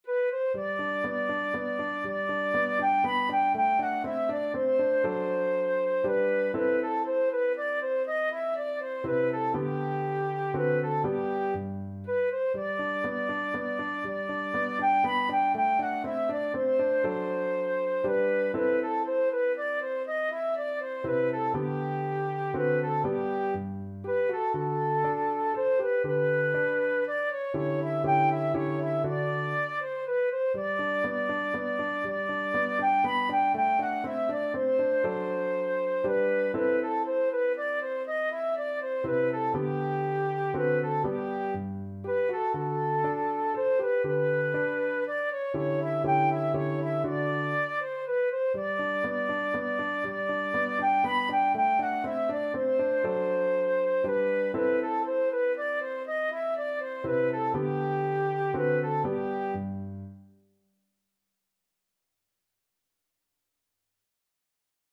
3/4 (View more 3/4 Music)
Classical (View more Classical Flute Music)